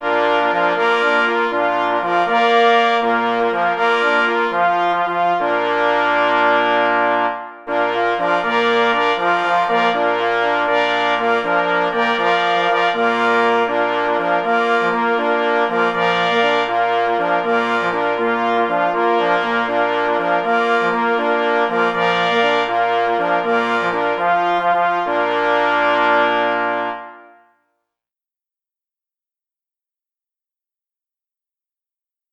Huberta”, wszystkie na duży zespół rogów myśliwskich.
Opis zasobu: miniatura na 3 plesy i 2 parforsy Tytuł […]